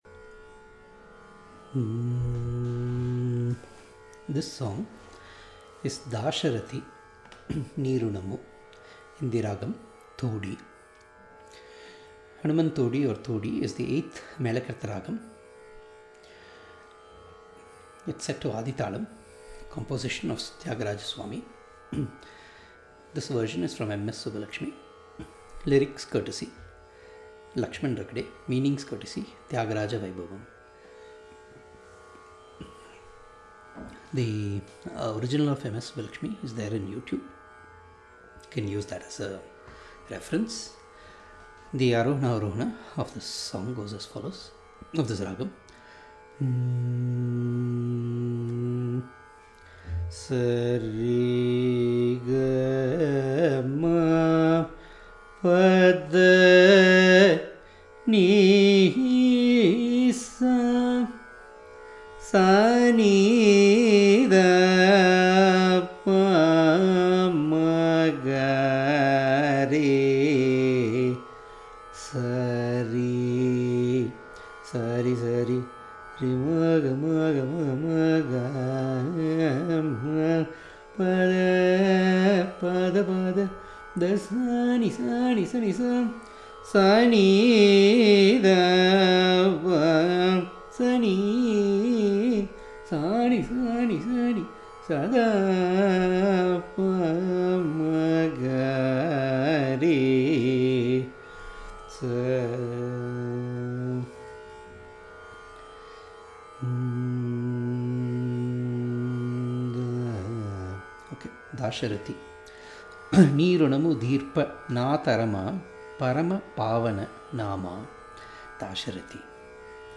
Ragam: Thodi {8th Melakartha Ragam}
ARO: S R1 G2 M1 P D1 N2 S ||
AVA: S N2 D1 P M1 G2 R1 S ||
Talam: Adi
dasarathi-thodi-class.mp3